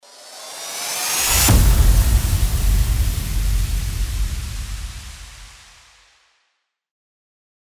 FX-748-RISING-IMPACT
FX-748-RISING-IMPACT.mp3